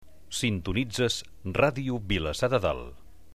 Identificació de l'emissora